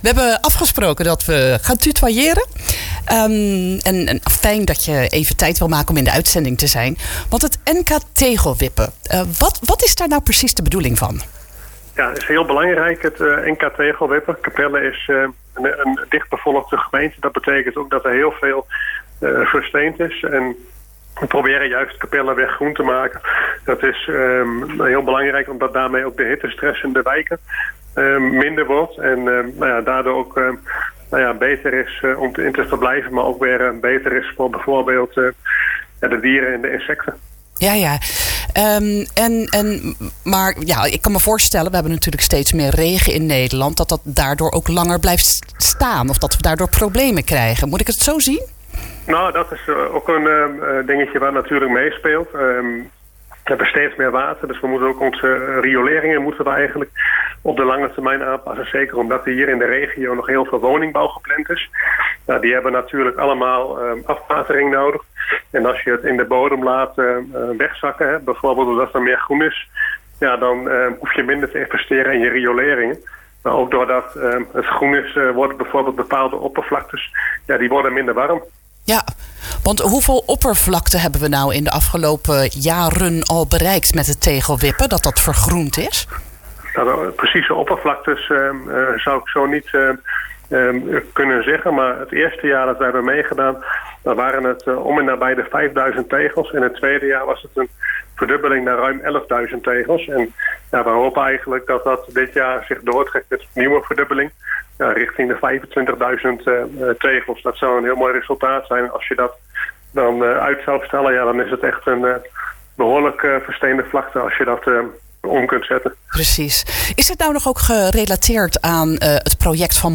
In het programma IJssel-nieuws riep hij iedereen op om zoveel mogelijk tegels te wippen.